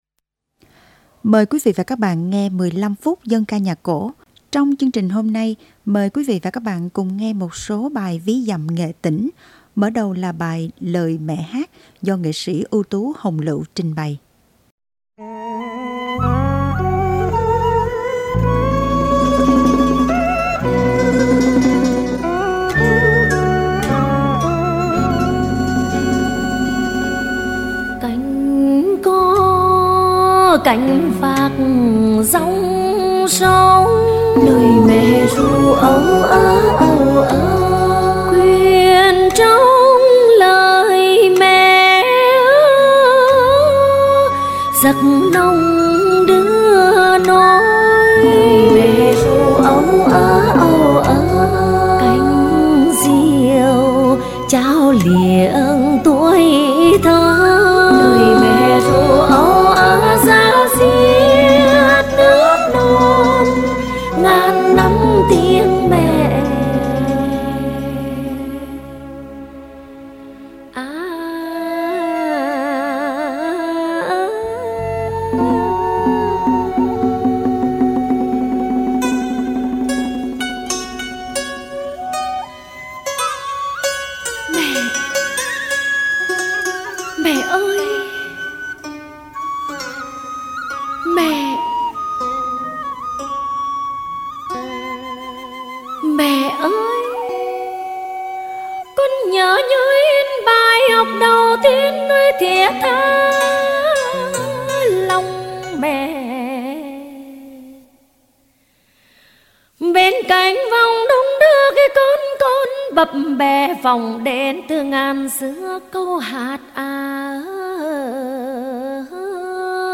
29-8-dan-ca-nhac-co.mp3